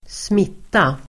Uttal: [²sm'it:a]